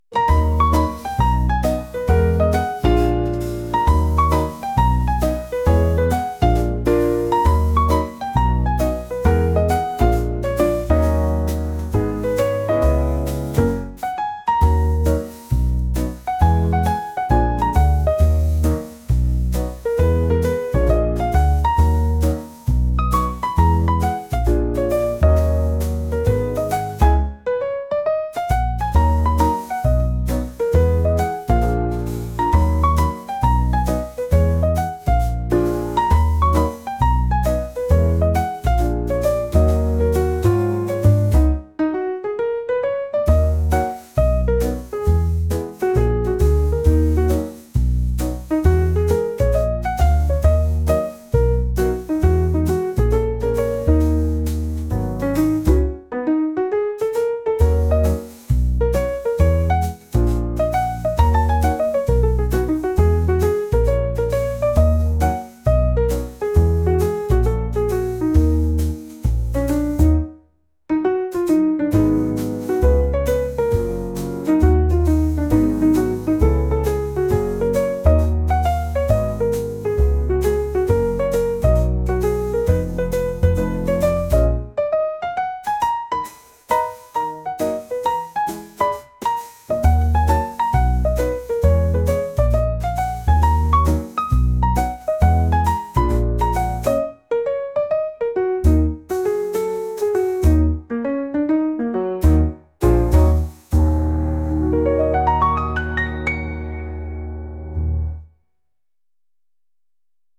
ゆったりすごすジャズのピアノ曲です。